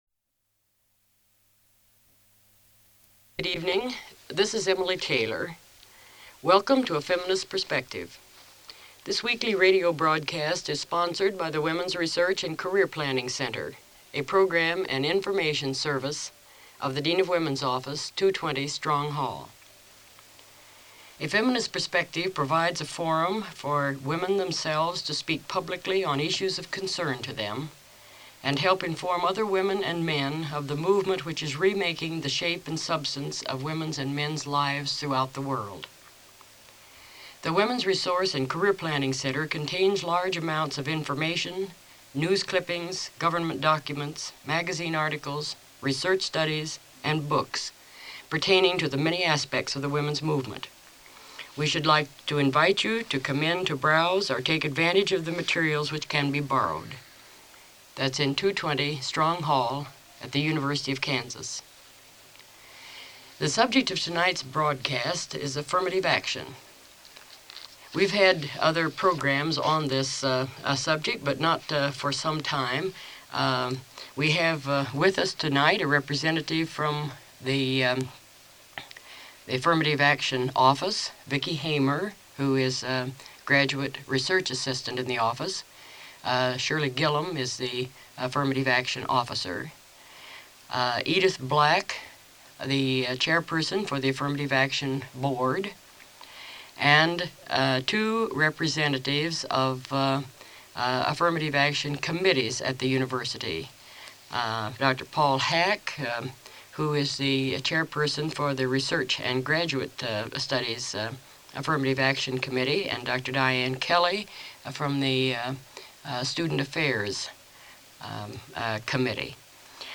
Feminist Perspective radio program
Radio talk shows